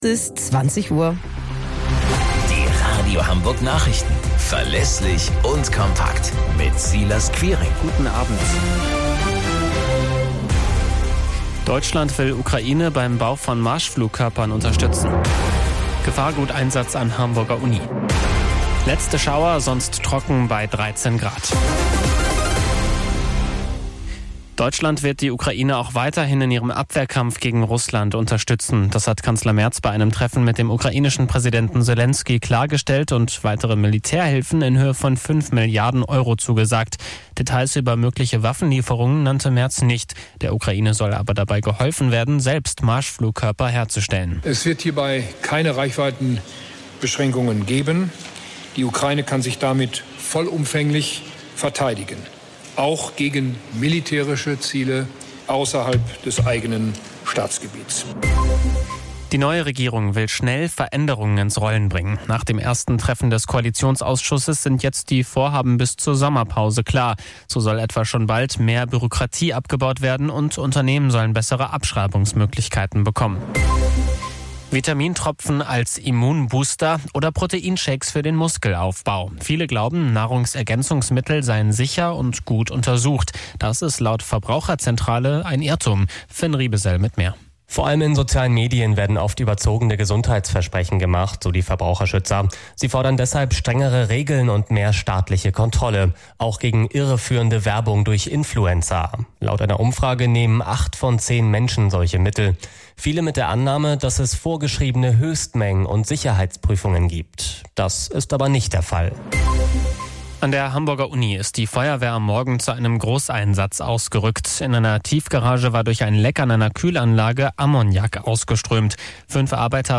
Radio Hamburg Nachrichten vom 30.05.2025 um 11 Uhr - 30.05.2025